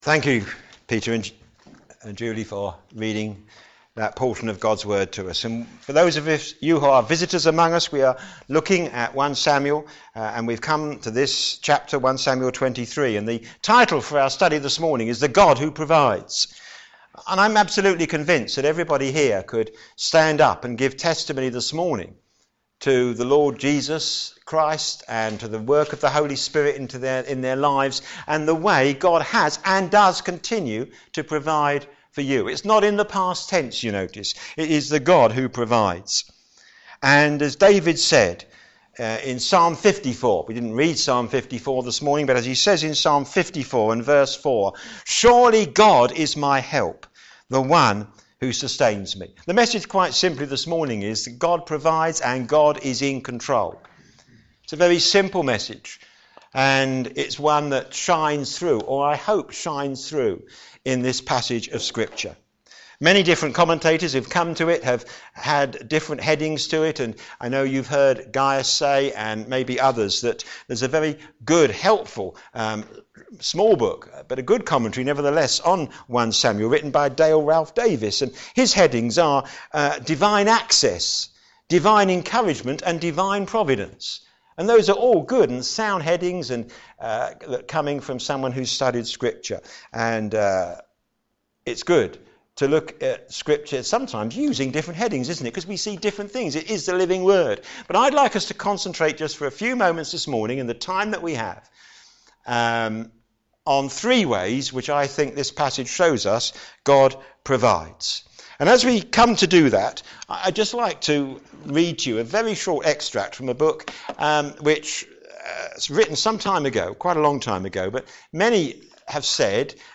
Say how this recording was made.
a.m. Service on Sun 21st Jun 2015